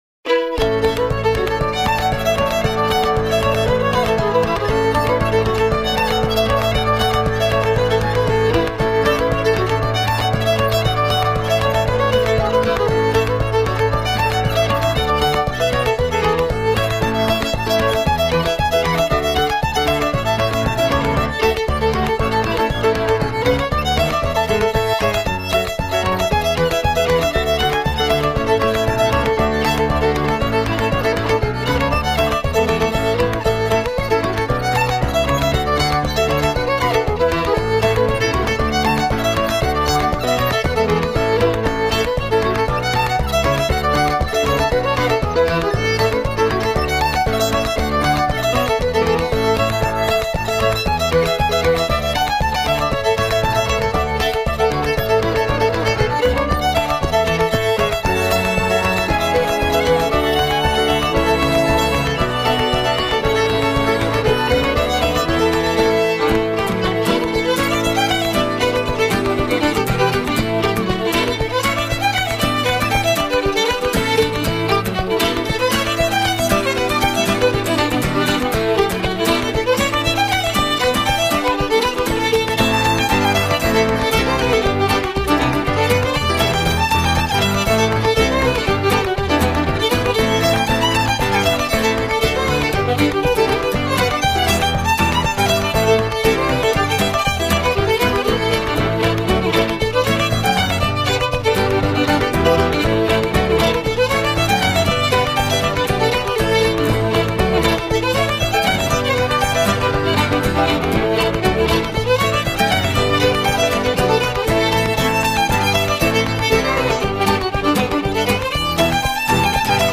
sans paroles